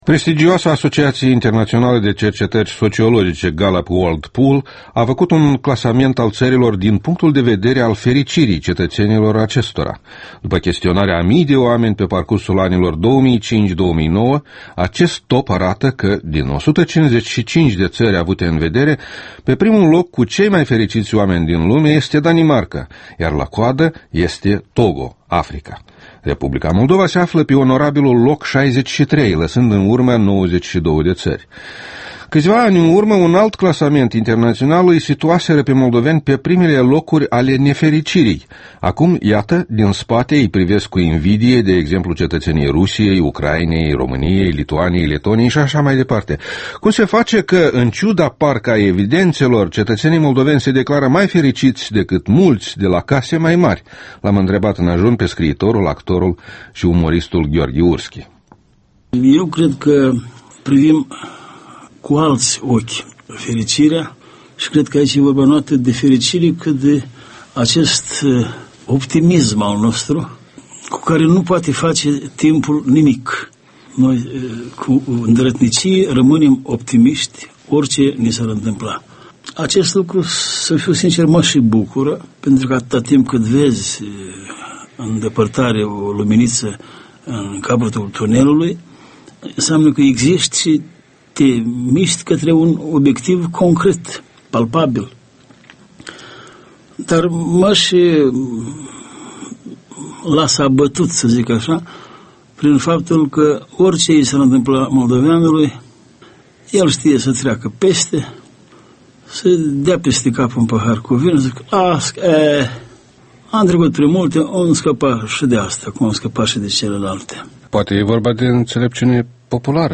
Interviul matinal EL: cu Gheorghe Urschi